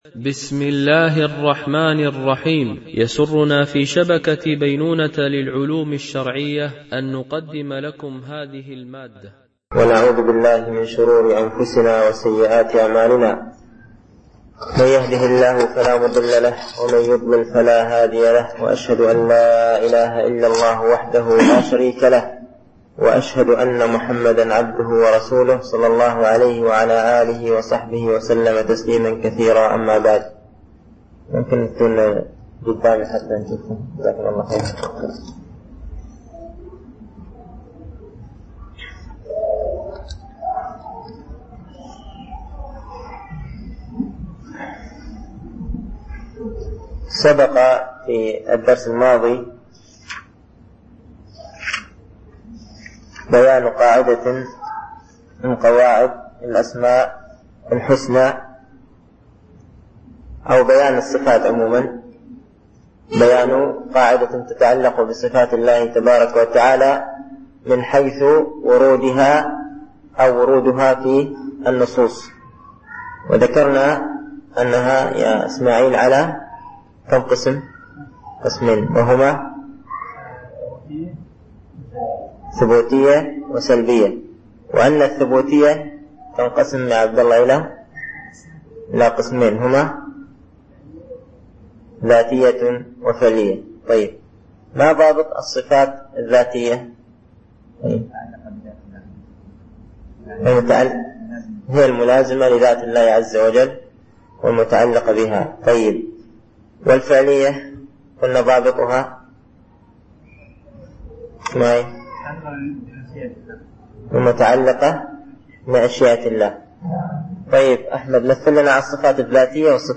الألبوم: شبكة بينونة للعلوم الشرعية التتبع: 27 المدة: 44:06 دقائق (10.13 م.بايت) التنسيق: MP3 Mono 22kHz 32Kbps (CBR)